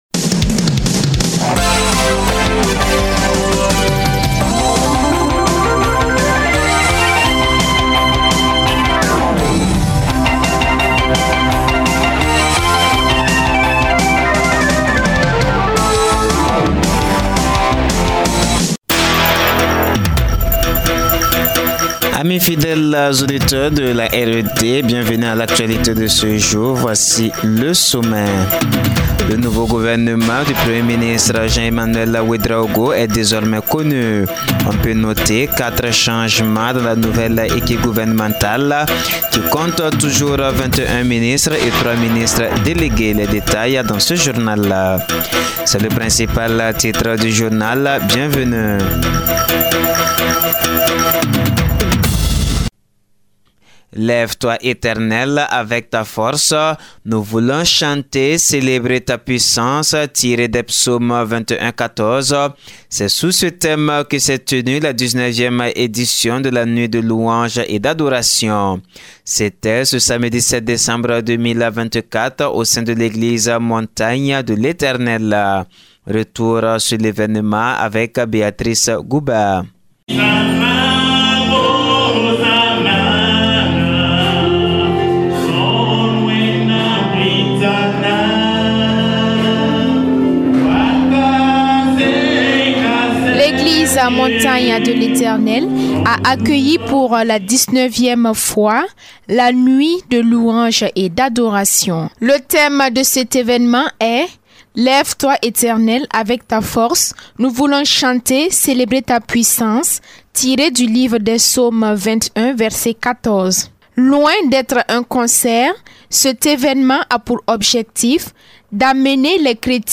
Journal parlé RED du lundi 09 décembre 2024